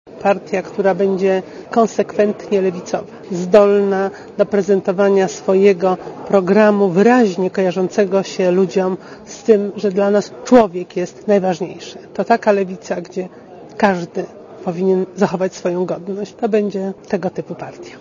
Posłuchaj komentarza nowej szefowej UP